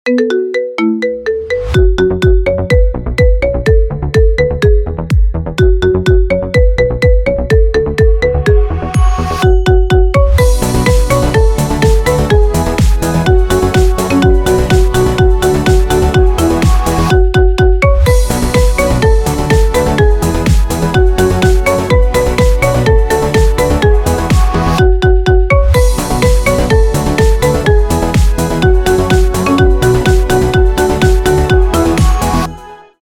Танцевальные рингтоны
маримба